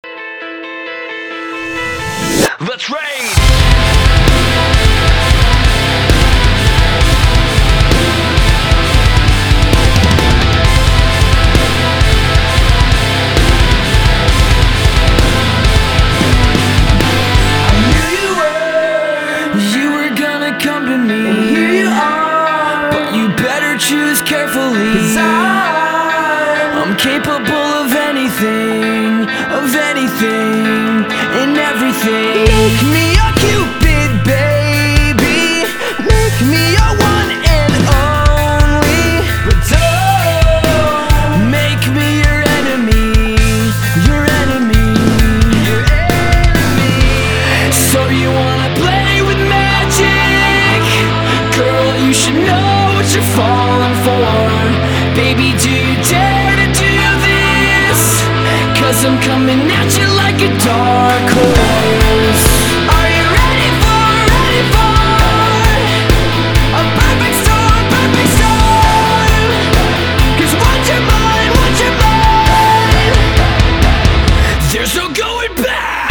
Рок-кавер